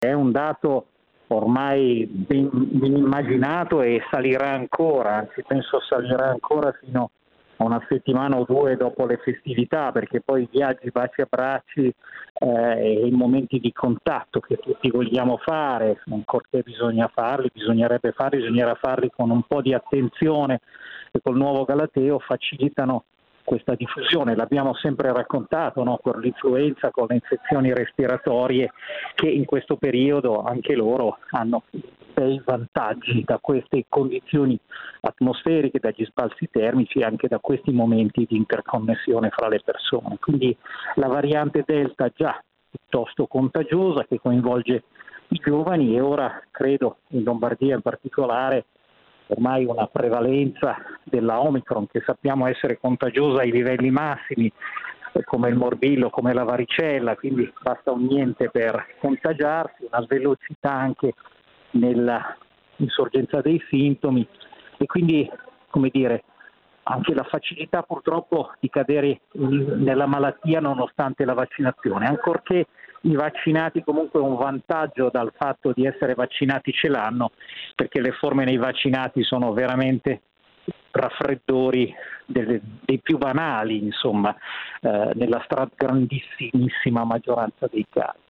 Il racconto della giornata di martedì 28 dicembre 2021 con le notizie principali del giornale radio delle 19.30. L’Italia va a lunghi passi verso i centomila contagi quotidiani, una settimana fa erano trentamila.